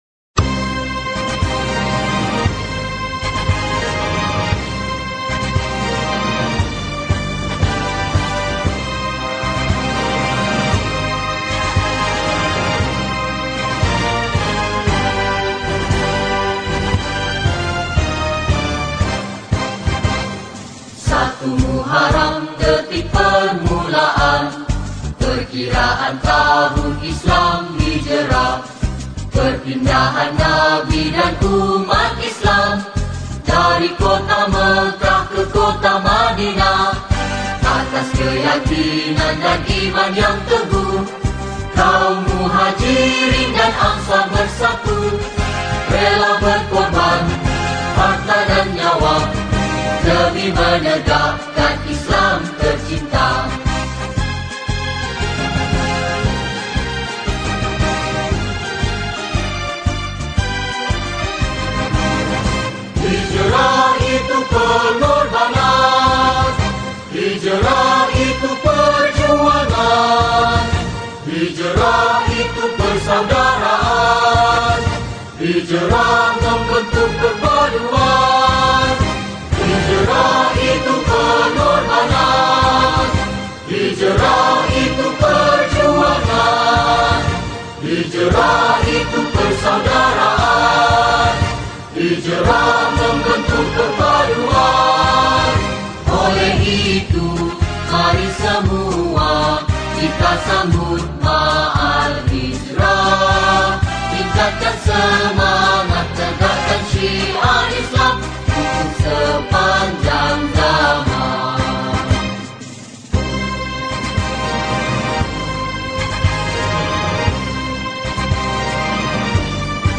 Nasyid Songs
Solo Recorder